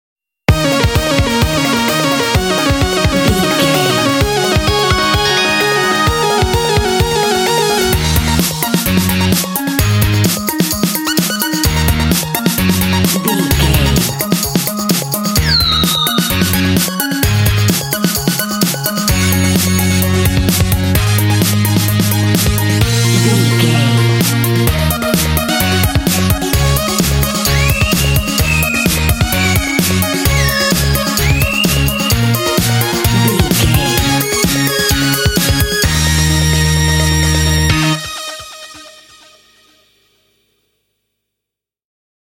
Epic / Action
Uplifting
Aeolian/Minor
E♭
fun
futuristic
happy
bouncy
lively
energetic
synthesiser
electric guitar
electronica